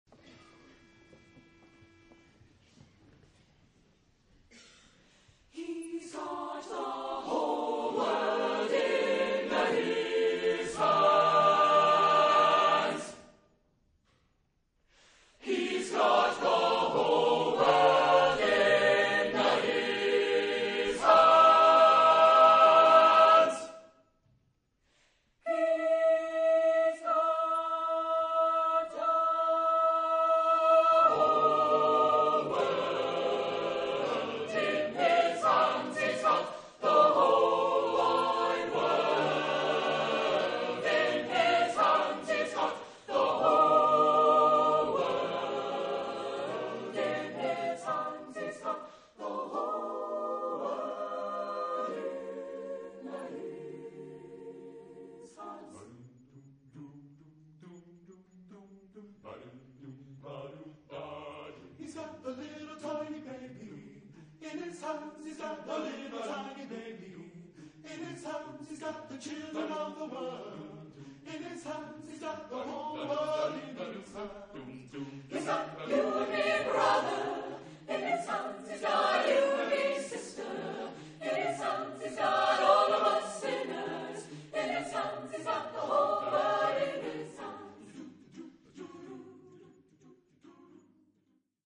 Género/Estilo/Forma: Espiritual ; Sagrado ; Profano
Carácter de la pieza : swing
Tipo de formación coral: SATB  (4 voces Coro mixto )